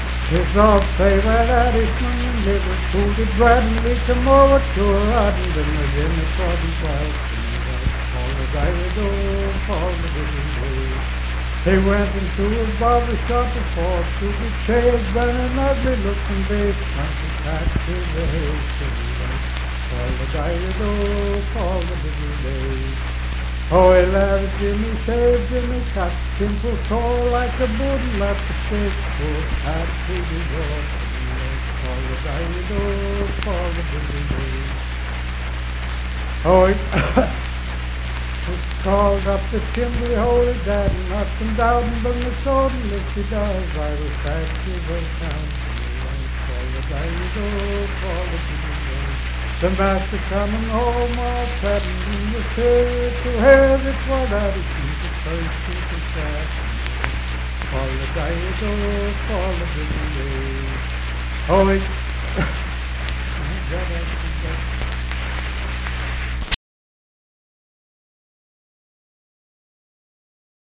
Unaccompanied vocal music performance
Ethnic Songs
Voice (sung)
Richwood (W. Va.), Nicholas County (W. Va.)